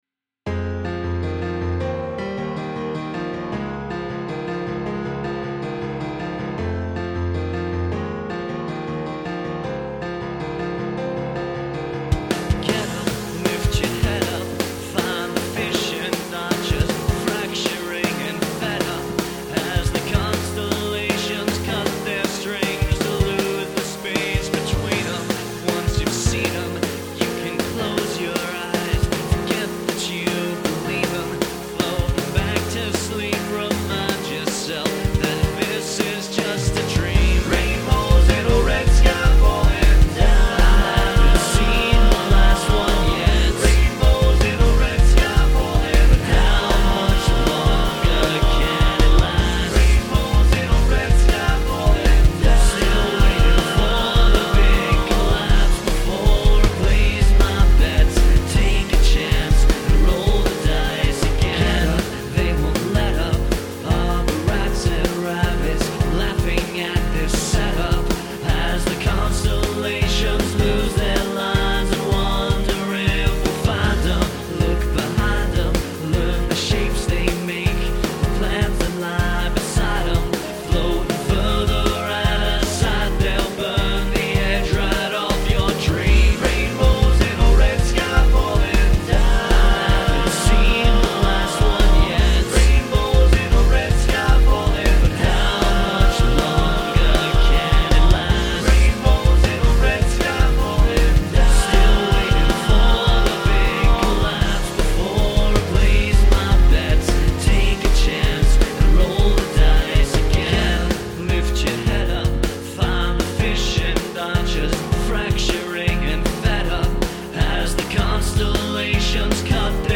The recordings themselves could be better, but that's what demos are for.
I had fun using the vocoder on this track. Also, ultra-auto-tuning for that Cher effect is fun when you are going for the robot feel.
Then I doubled those tracks to make six background vocal tracks, and then I doubled the lead and added an additional dropped octave of the vocal lead. Nine vocal tracks on a chorus.